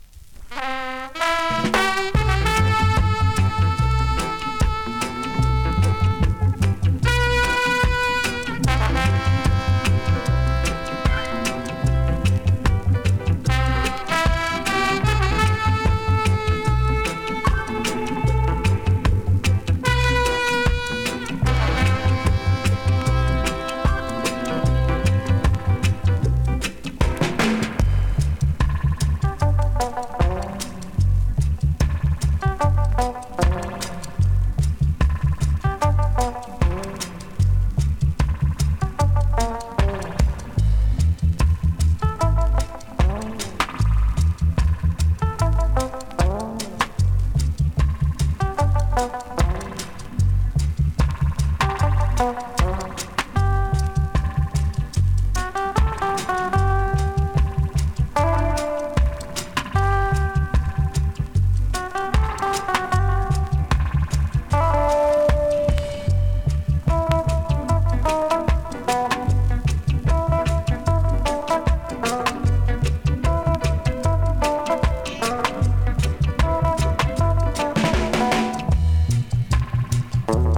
NEW IN!SKA〜REGGAE
スリキズ、ノイズかなり少なめの